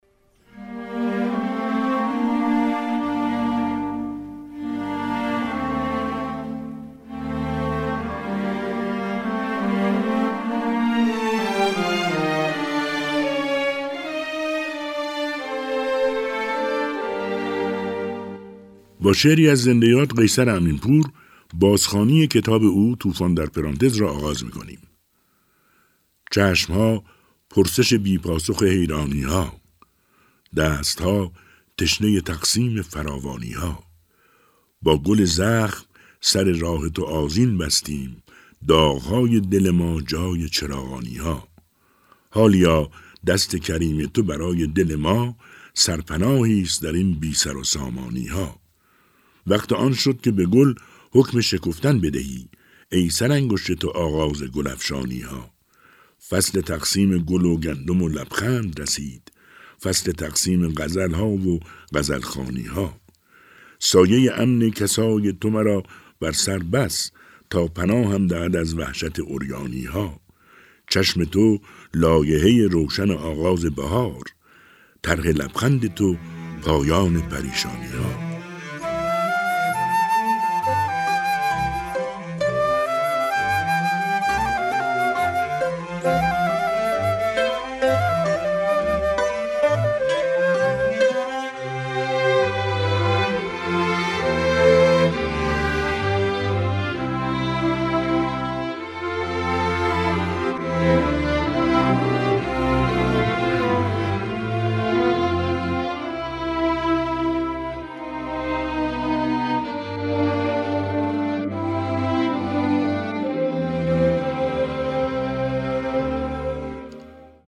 شعرهای قیصر امین‌پور با صدای بهروز رضوی + صوت
قسمتی از شعرخوانی بهروز رضوی در بازخوانی کتاب «توفان در پرانتز» در برنامه «کتاب شب» رادیو تهران را در زیر می‌شنوید: